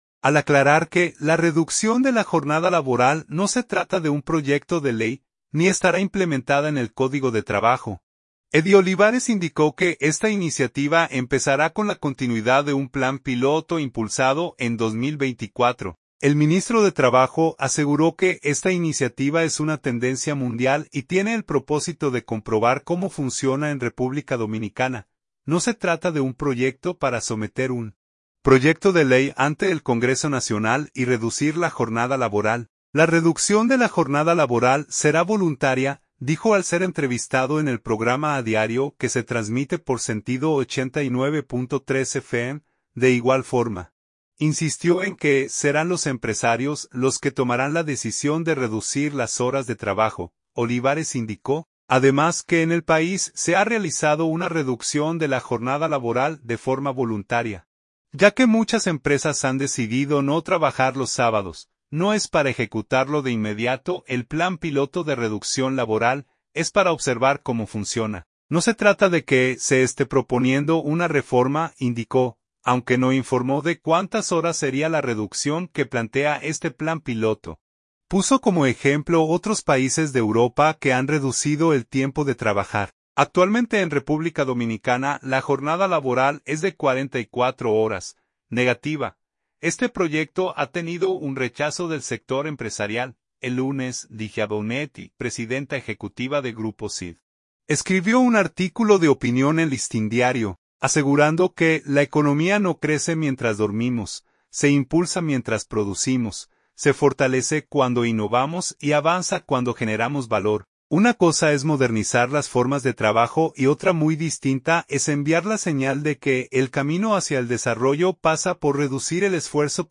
“No se trata de un proyecto para someter un proyecto de ley (ante el Congreso Nacional) y reducir la jornada laboral; la reducción de la jornada laboral será voluntaria”, dijo al ser entrevistado en el programa “A Diario”, que se transmite por Sentido 89.3 fm.